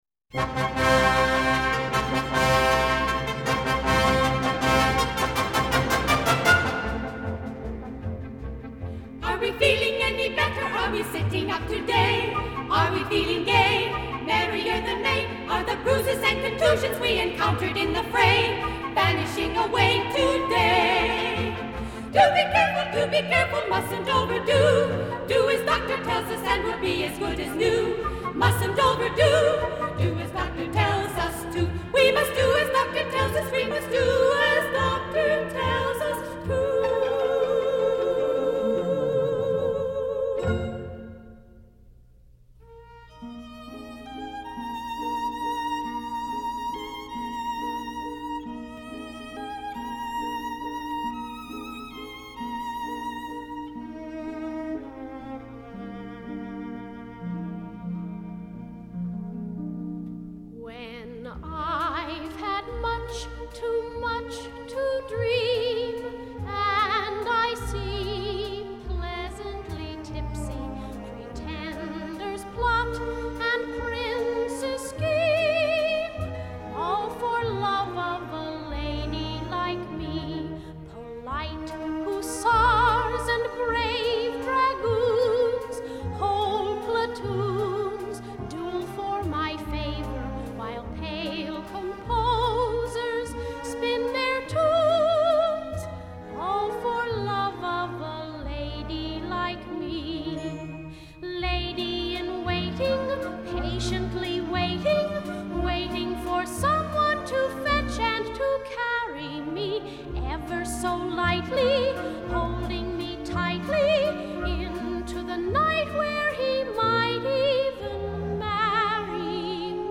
3.  Musical Theater
(vocal)